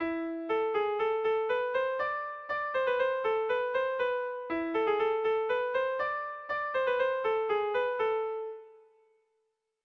Sehaskakoa
ABAB2